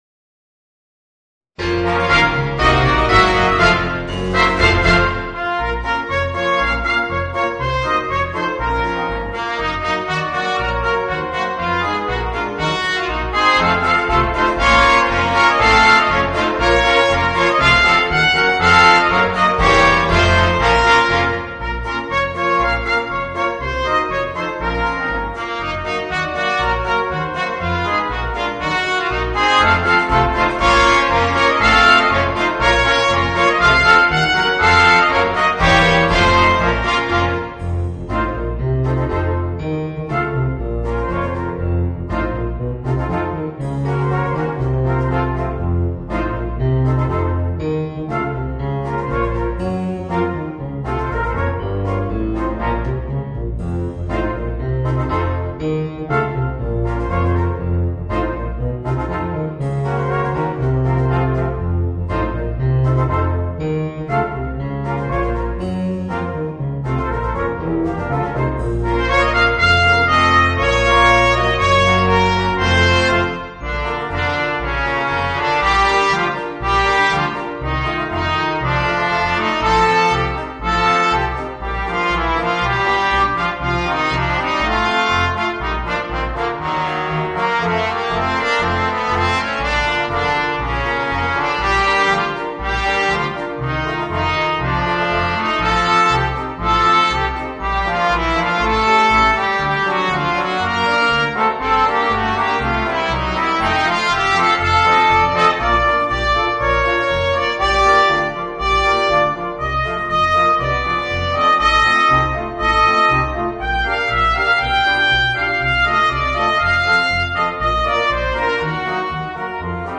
Voicing: 5 - Part Ensemble and Piano / Keyboard